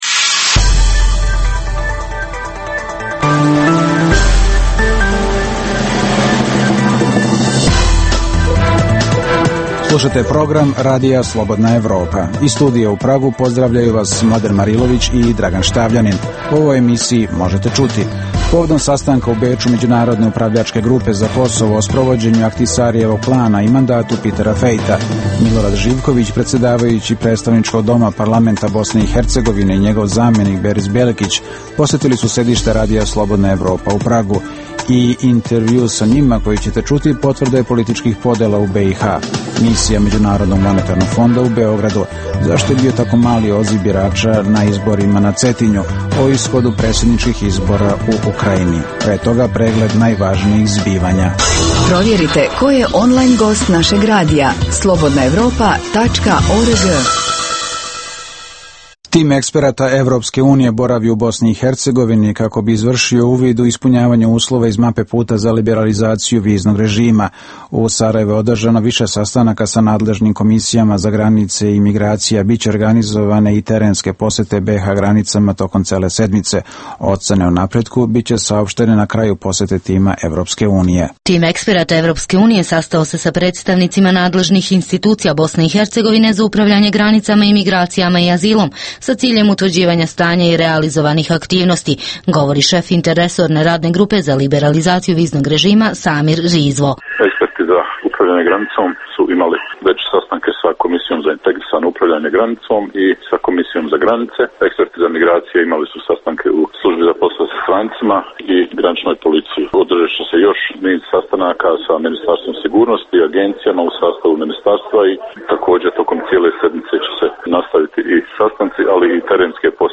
I intervju sa njima koji možete čuti, potvrda je političkih podela u BiH.